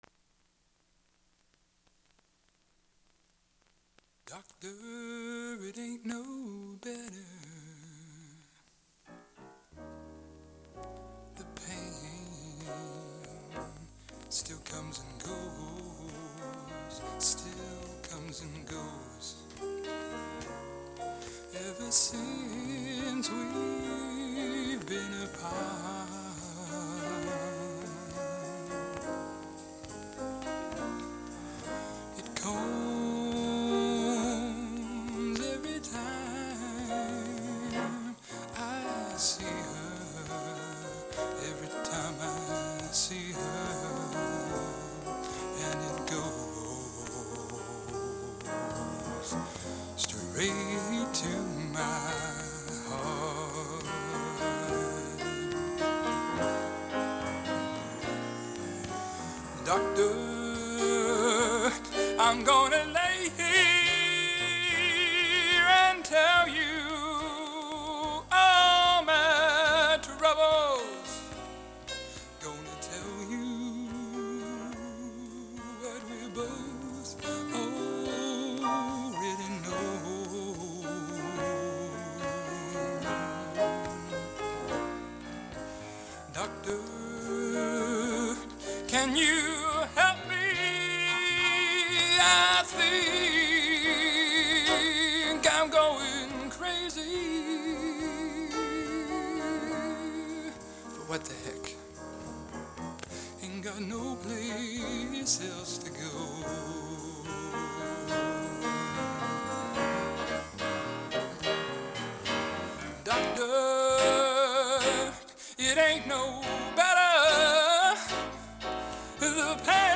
Genre: | Type: Featuring Hall of Famer |Studio Recording